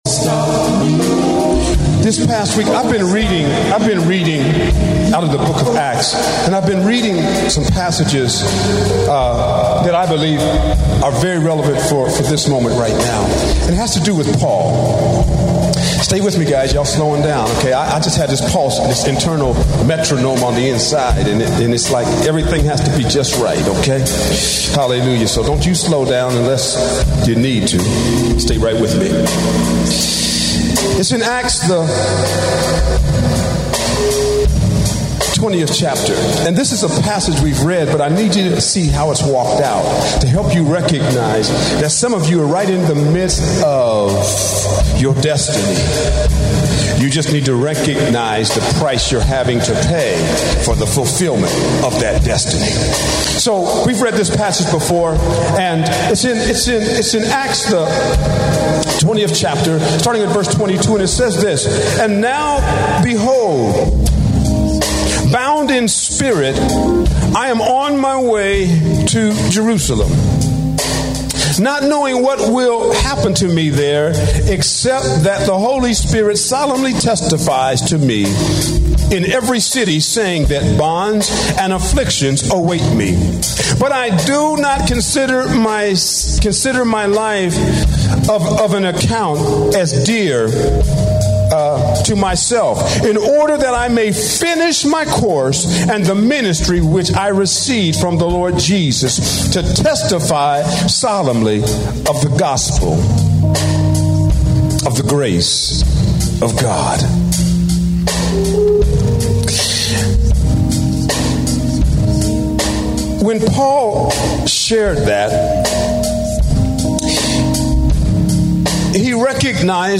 Teachings